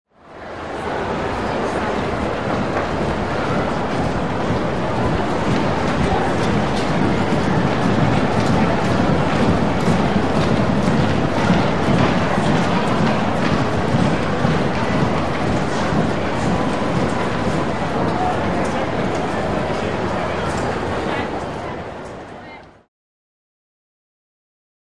Players, Heavy Walking By In Tunnel, Room Reverb. Various Sports, Players Approaching, Prior To Game.